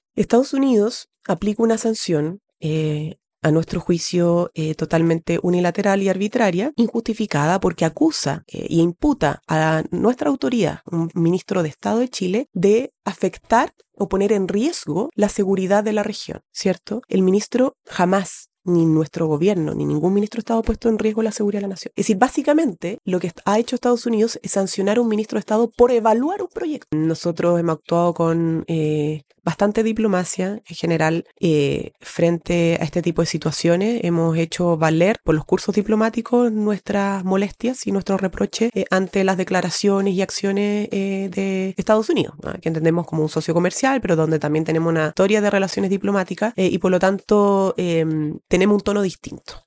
La ministra vocera de Gobierno, Camila Vallejo, defendió este miércoles al ministro de Transportes y Telecomunicaciones, Juan Carlos Muñoz, luego de que Estados Unidos revocara su visa, calificando la medida como unilateral, arbitraria e injustificada.